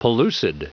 Prononciation du mot pellucid en anglais (fichier audio)
Prononciation du mot : pellucid